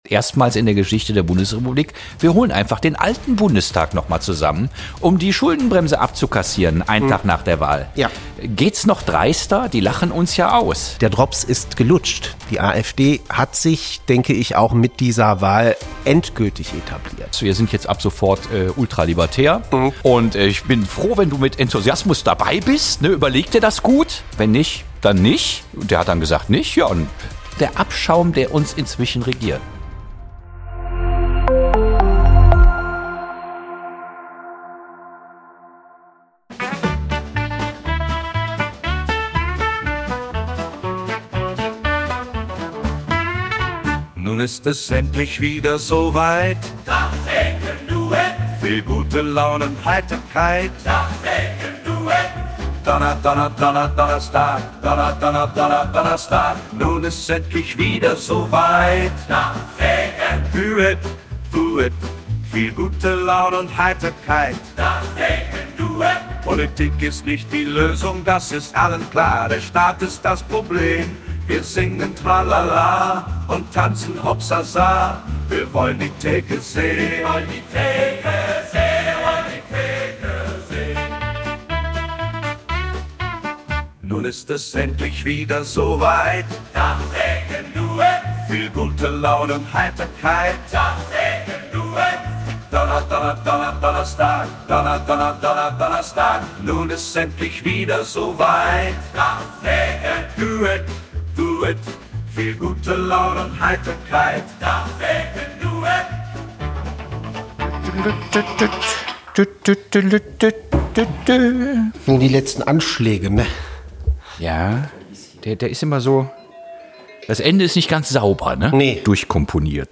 Kleine Redaktionskonferenz am Tresen